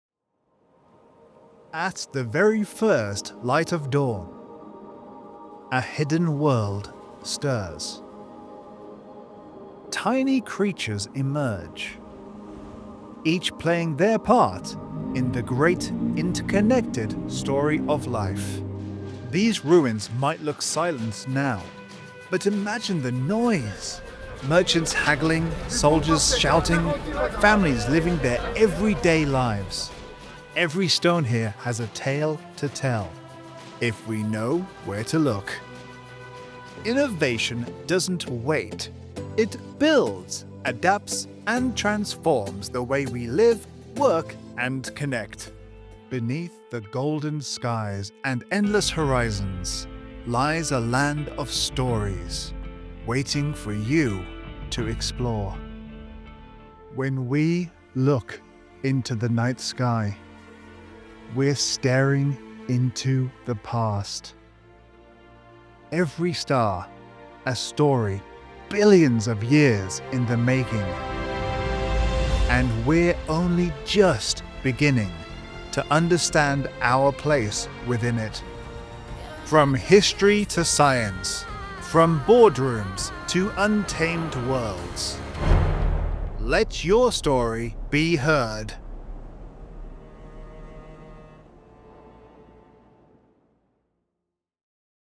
Male & female announcers for all projects.
0905Narration_Documentary_Demo_2.mp3